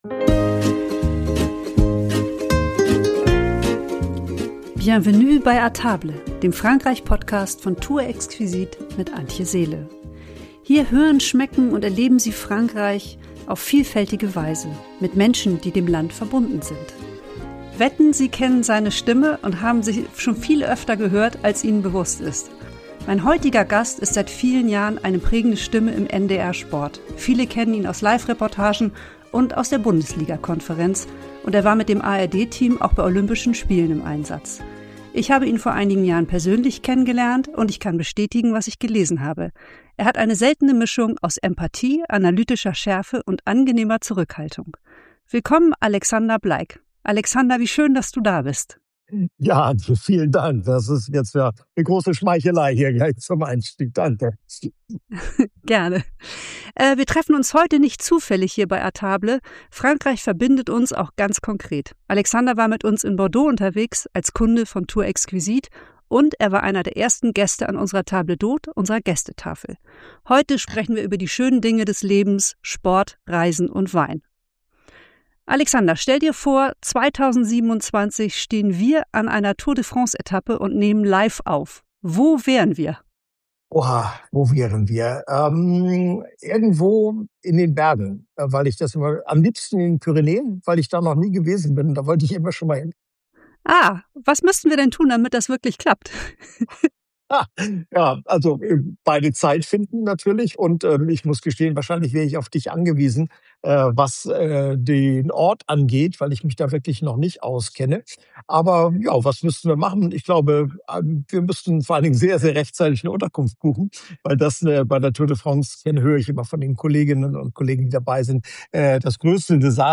Ein Gespräch über Stimme und Haltung, über Märkte am Morgen, Etappen in den Pyrenäen, gereifte Bordeaux-Weine – und darüber, wie Reisen und Sport uns prägen. Hinweis: Wenn Sie sich vor allem für Frankreich, Reisen und Genuss interessieren: Ab Minute 25:57 geht es ausführlich um Bordeaux, Weinregionen und eine Radreise bis Biarritz.